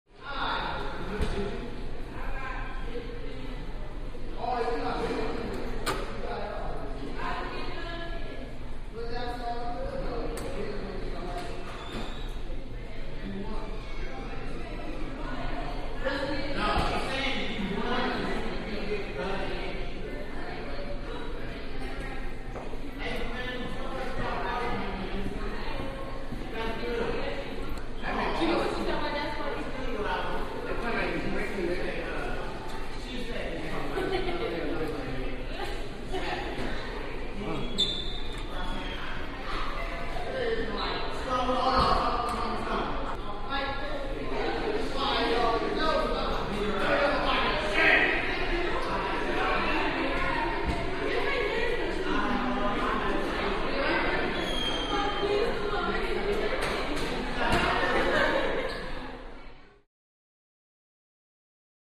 High School Gym | Sneak On The Lot
High School Gymnasium Ambience, Distant To Close Up Reverberant Voices